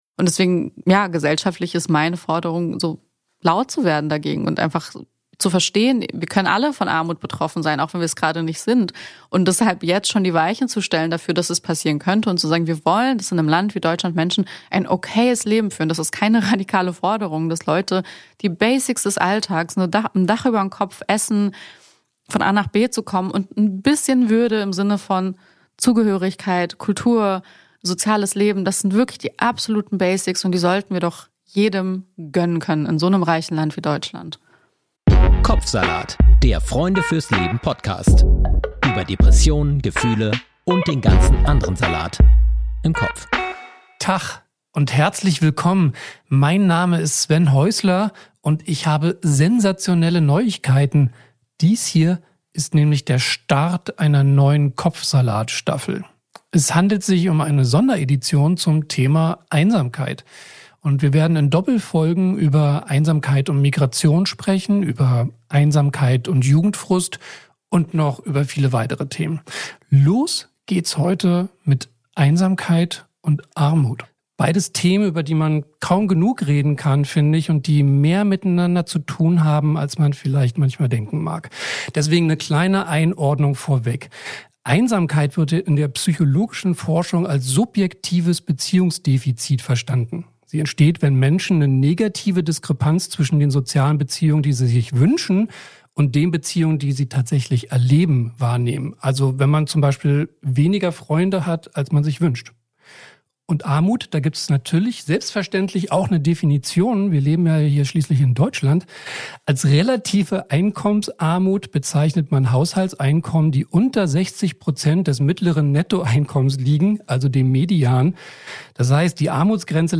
Die Folge gibt Einblicke in persönliche Erfahrungen, familiäre Dynamiken und gesellschaftliche Rahmenbedingungen rund um Armut und Einsamkeit. Das Gespräch zeigt, wie stark Armut das Leben und zwischenmenschliche Beziehungen prägen kann – während sie gleichzeitig für viele unsichtbar bleibt.